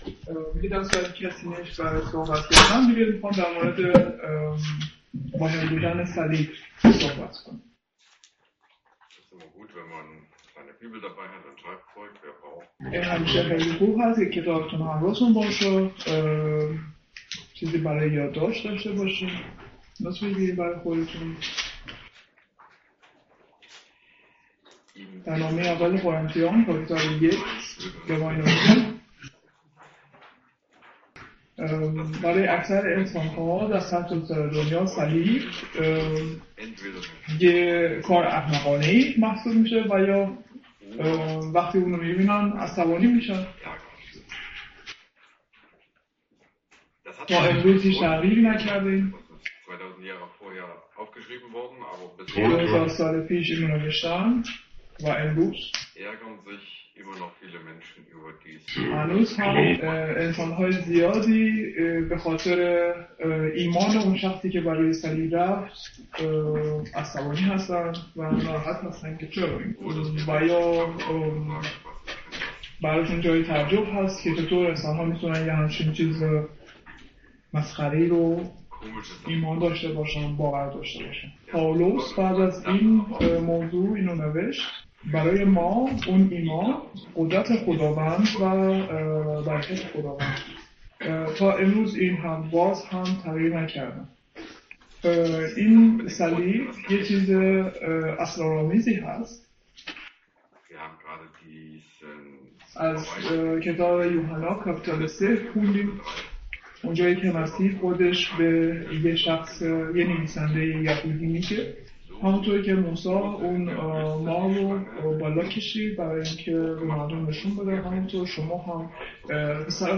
Übersetztung in Farsi